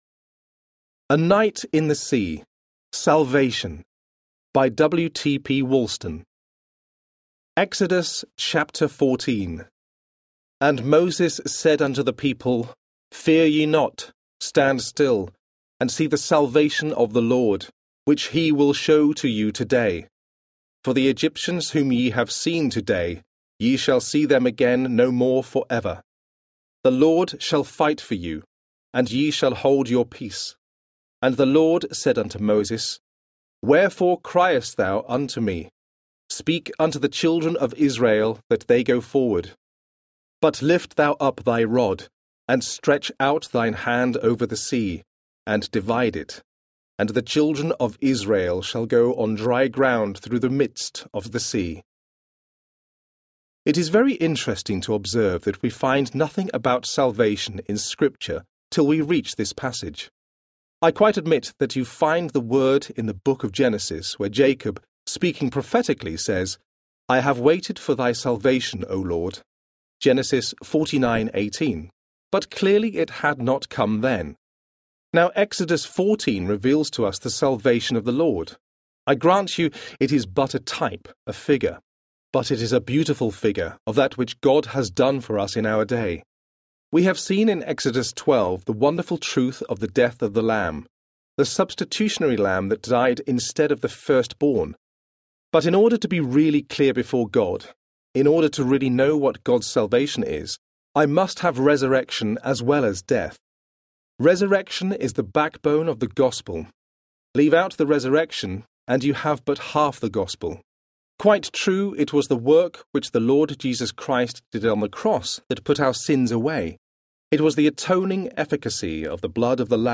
A Night in the Sea Audiobook
Narrator
0.72 Hrs. – Unabridged